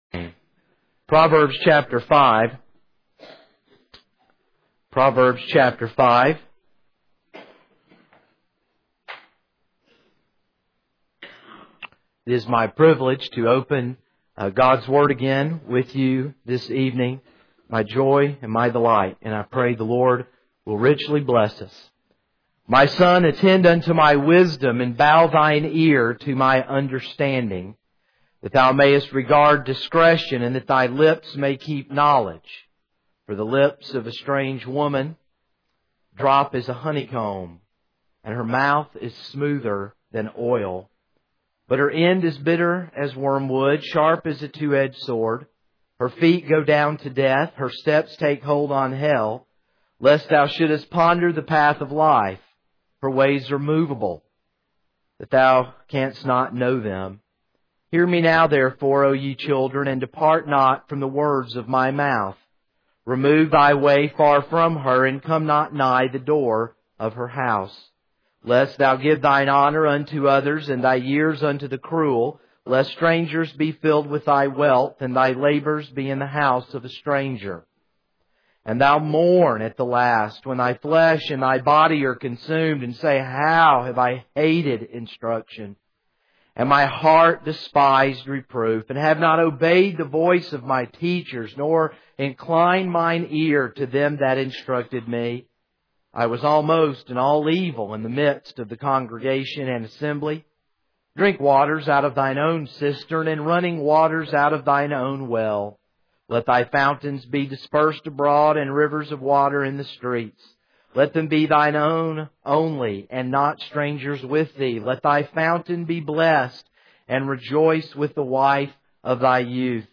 This is a sermon on Proverbs 5.